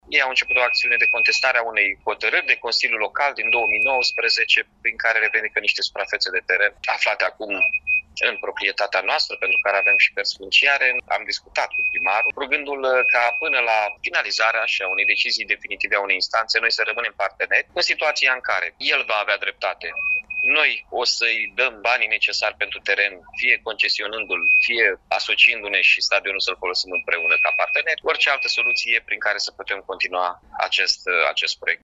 În replică, primarul Iașiului, Mihai Chirica, a precizat că respectivul teren, situat pe șes Bahlui, este inventariat în domeniul privat al municipiului reședință, conform unei hotărâri judecătorești, și, ulterior, a fost intabulat.